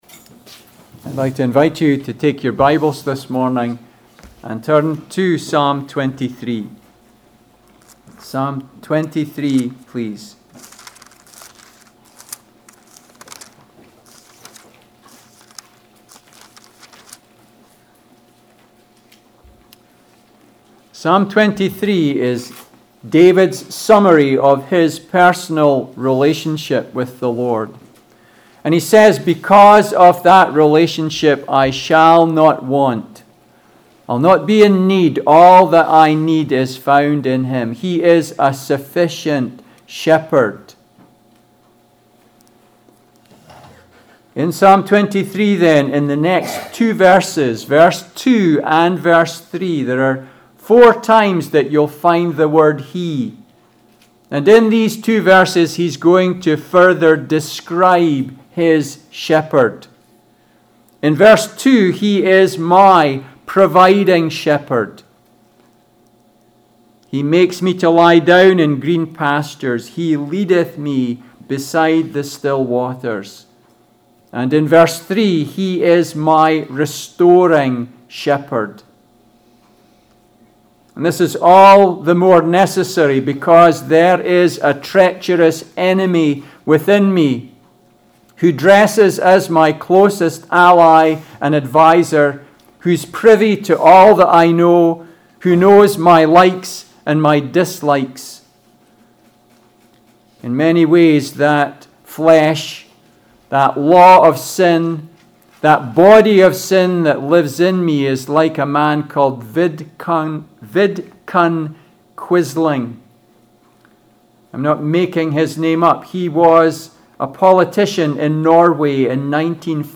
Passage: Psalm 23 Service Type: Sunday Service